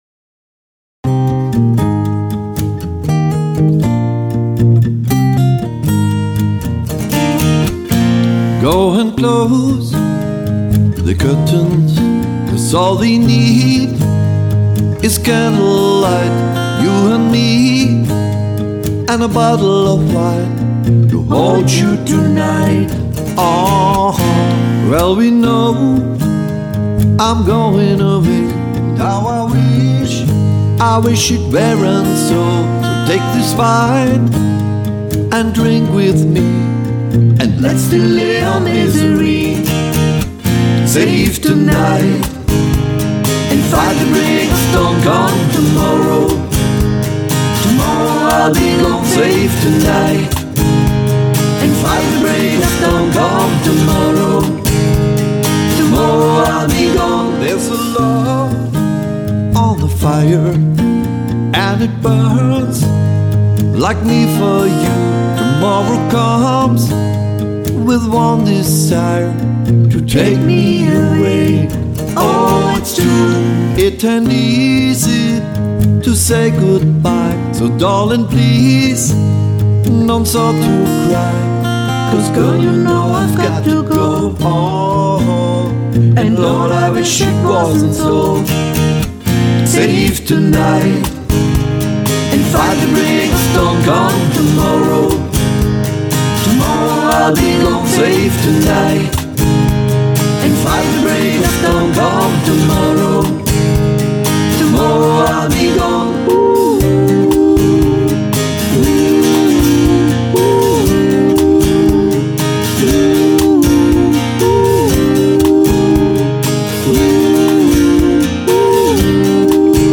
Acoustic Pop. New Country und Irish Folk
• Duo/Trio/Quartett
• Coverband
• Gitarristen